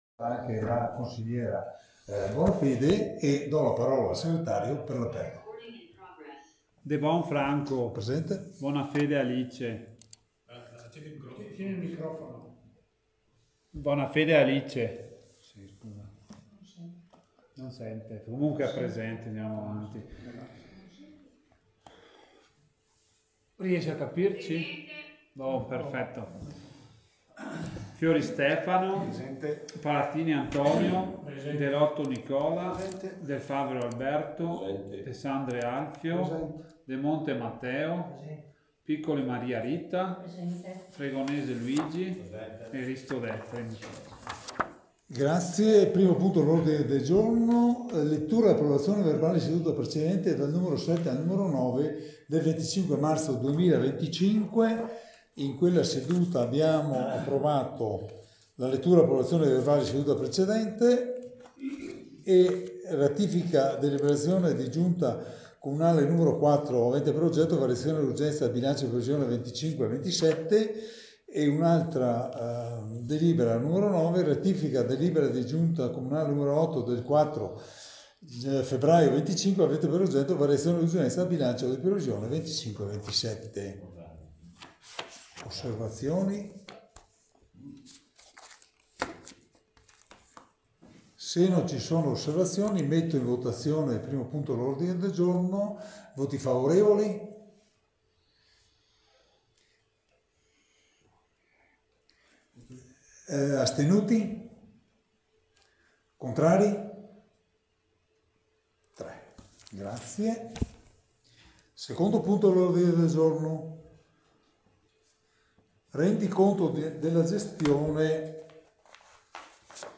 Registrazione audio Consiglio Comunale